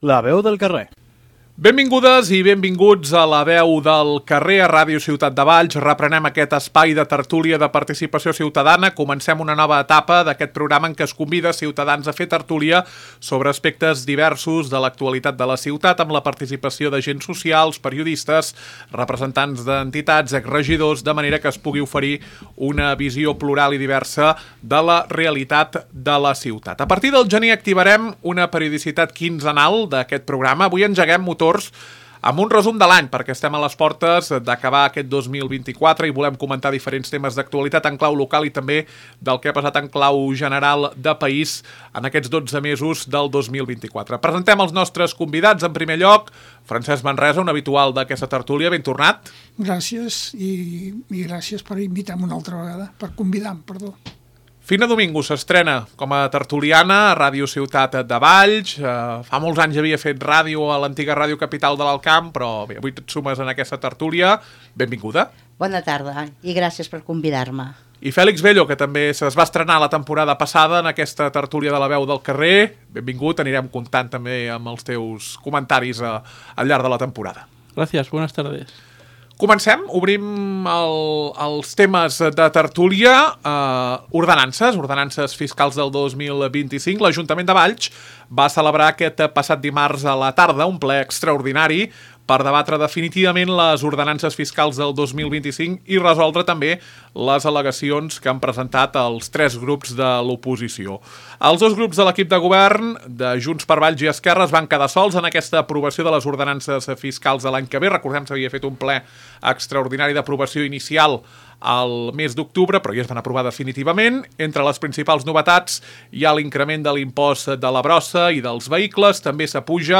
A Ràdio Ciutat de Valls us oferim La veu del carrer, un espai de tertúlia mensual de participació ciutadana.